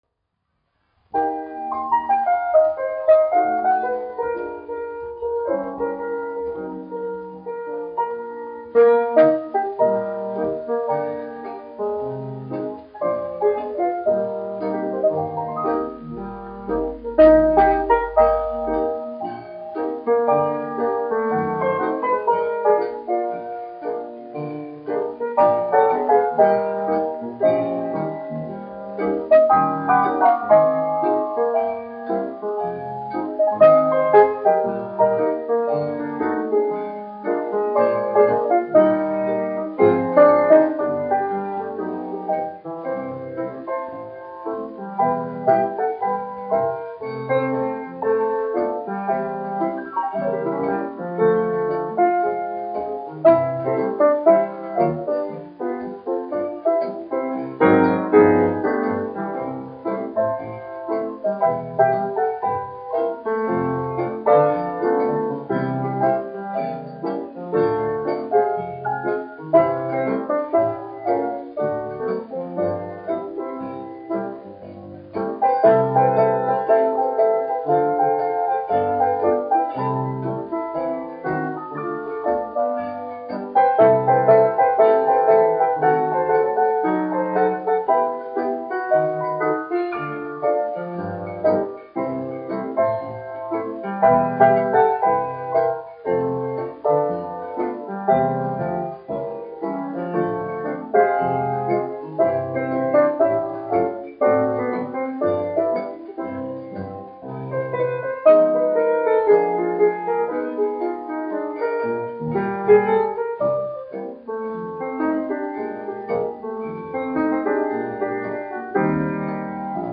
1 skpl. : analogs, 78 apgr/min, mono ; 25 cm
Džezs
Populārā instrumentālā mūzika
Skaņuplate